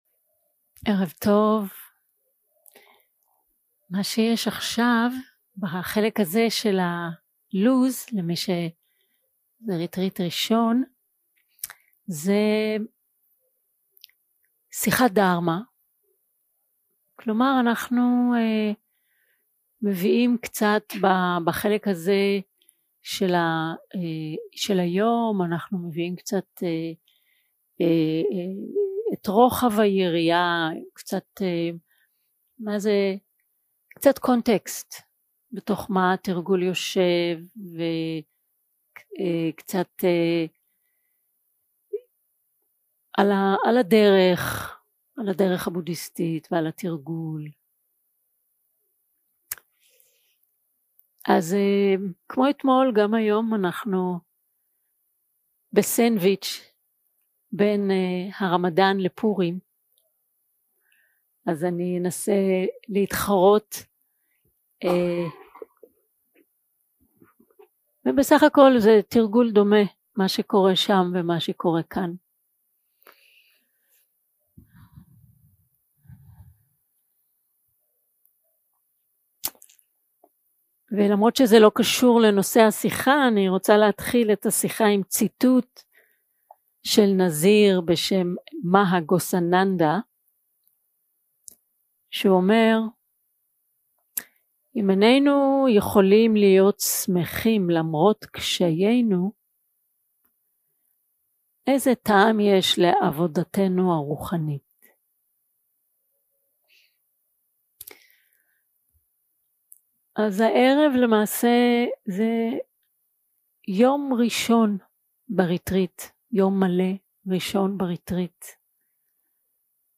יום 2 – הקלטה 4 – ערב – שיחת דהארמה – אנחנו לא לבד + חמש הרוחות 0:00 0:00 סוג ההקלטה: Dharma type: Dharma Talks שפת ההקלטה: Dharma talk language: Hebrew